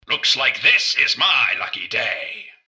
genericrobot_alerttocombat1.mp3